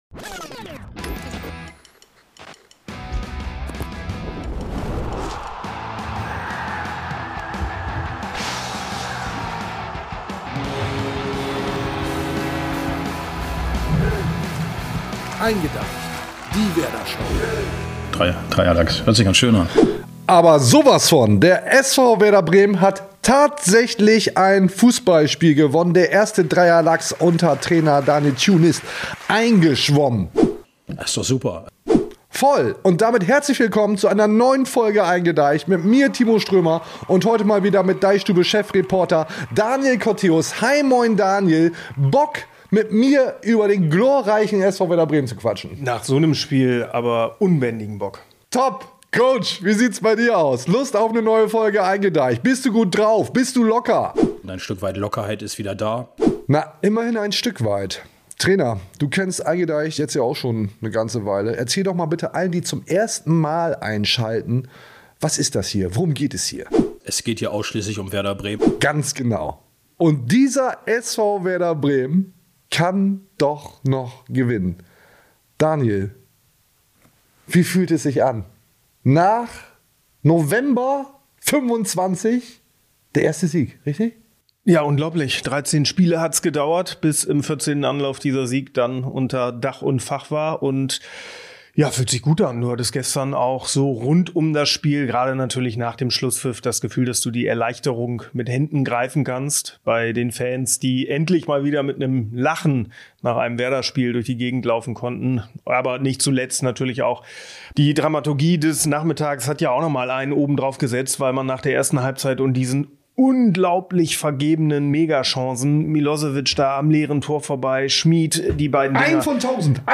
Denn in der Werder-Podcast-Show eingeDEICHt, gesendet aus dem DeichStube-Office, erwartet Euch wie immer eine Vollgas-Veranstaltung vollgestopft mit den Themen, die die Fans des SV Werder Bremen beschäftigen.
Viel zu viele Einspieler, allerlei Blödsinn, schlechte Wortwitze, dumme Sprüche, manchmal Werder-Expertise.